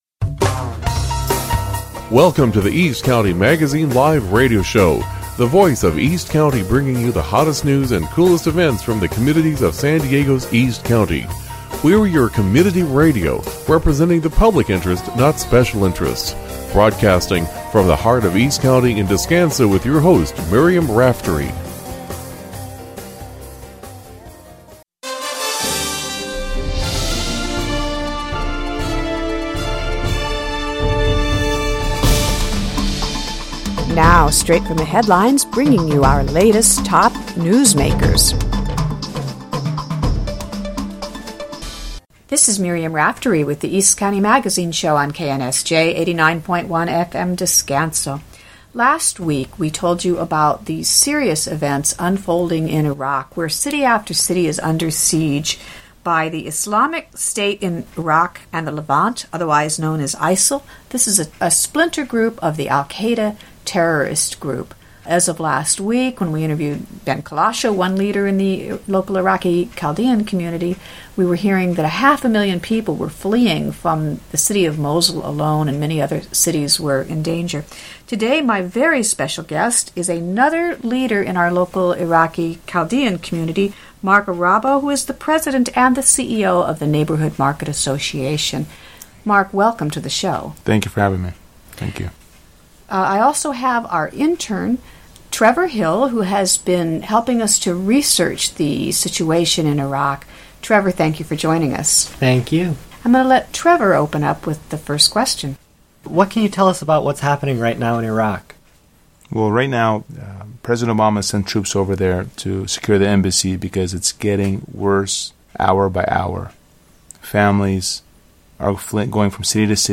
East County Magazine Live! Radio Show